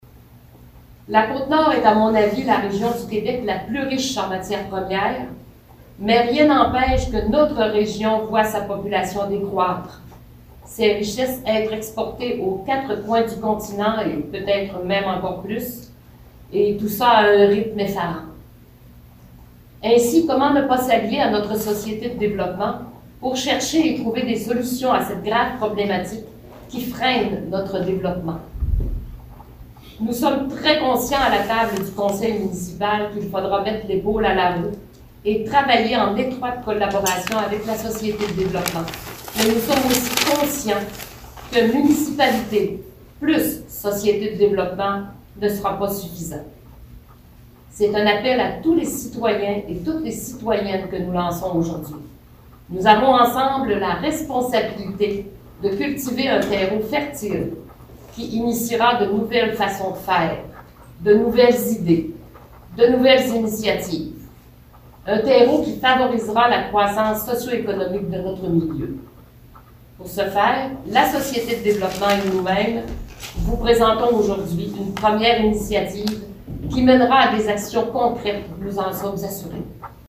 Sur invitation d'une conférence de presse mercredi 1er décembre 2021, la Société de développement de Sacré-Cœur a annoncé la mise en place d'un comité qui s'appellera Sacré-Cœur, ensemble, bâtissons notre avenir.
Madame Lise Boulianne mairesse de la municipalité, se désole de voir que la pénurie de main-d'œuvre a supprimé beaucoup de services dans cette petite localité de moins de 2000 habitants.
Lise-Boulianne-discours-conference-societe.mp3